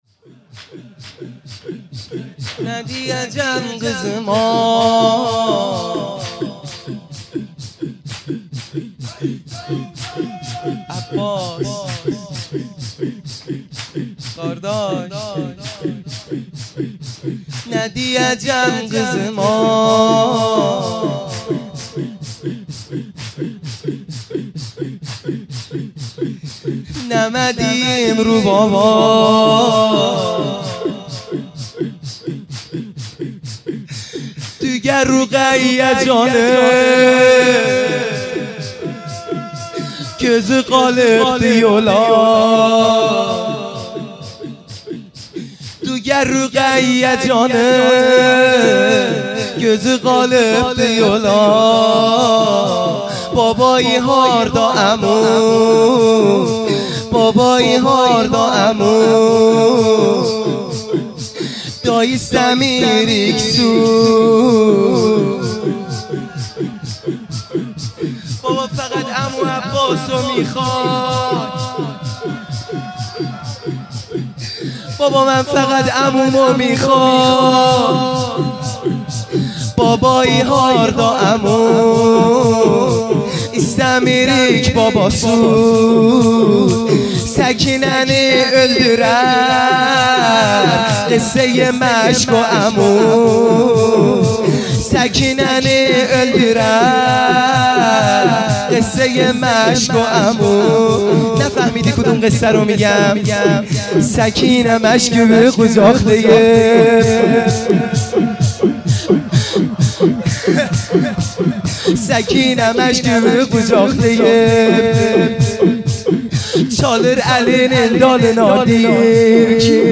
لطمه زنی | نه دییجم غیزیما
شب نهم محرم الحرام ۱۳۹۶